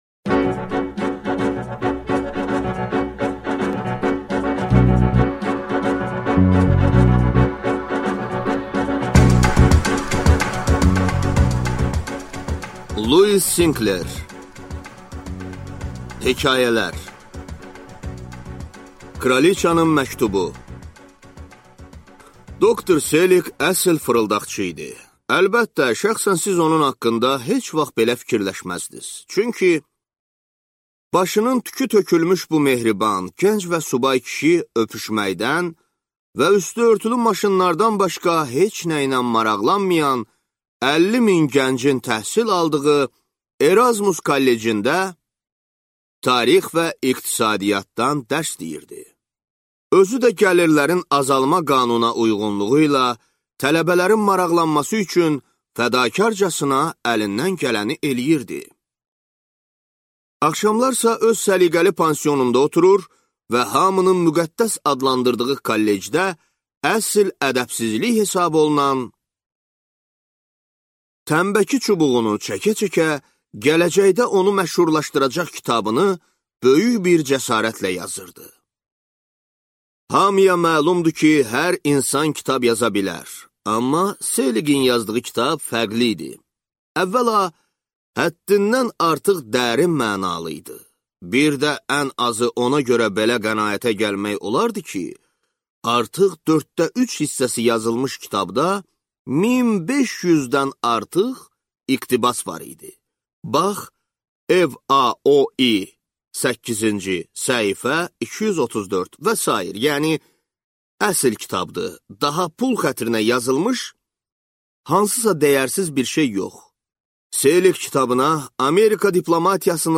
Аудиокнига Hekayələr | Библиотека аудиокниг